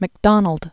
(mĭk-dŏnəld), Sir John Alexander 1815-1891.